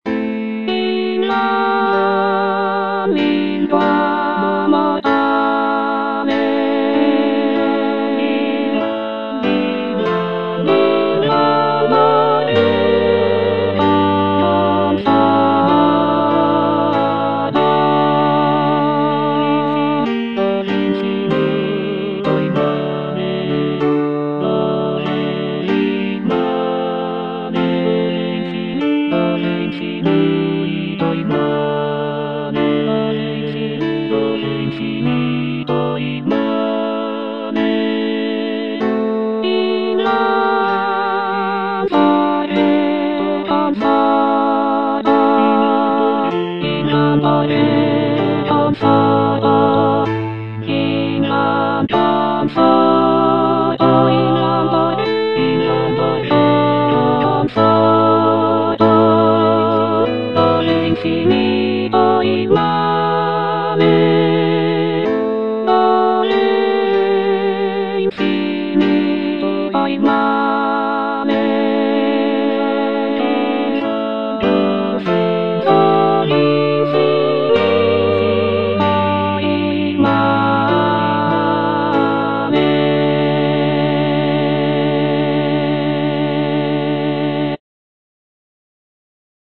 C. MONTEVERDI - LAMENTO D'ARIANNA (VERSION 2) Coro I: Invan lingua mortale (soprano II) (Emphasised voice and other voices) Ads stop: auto-stop
The music is characterized by its expressive melodies and poignant harmonies, making it a powerful and moving example of early Baroque vocal music.